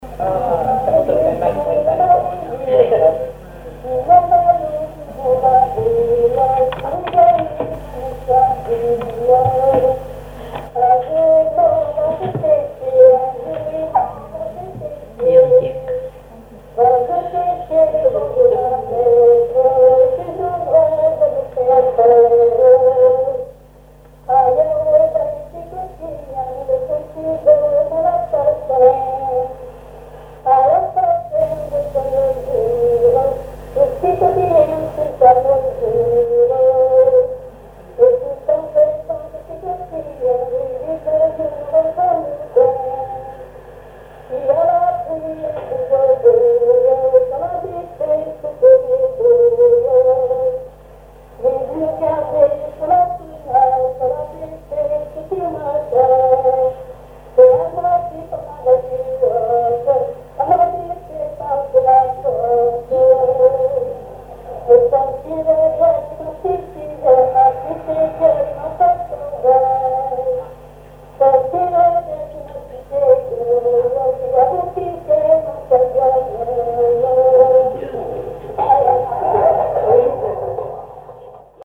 Veillée de chansons
Pièce musicale inédite